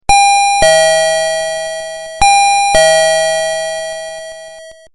02. Door Bell II
• Dual tone melody
• 3 kinds of songs (Ding-Dong, Ding-Dong/Ding-Dong and Westminster chime)